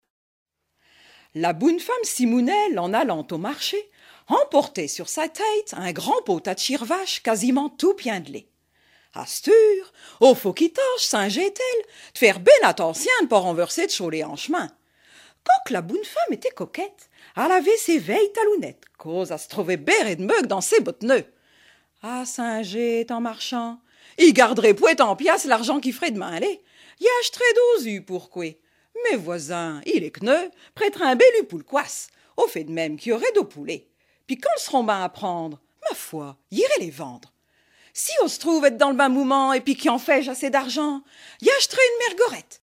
Genre fable
Catégorie Récit